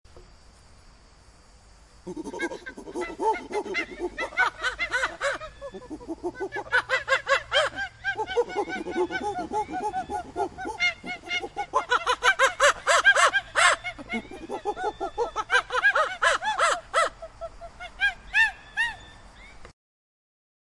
Download Monkey sound effect for free.
Monkey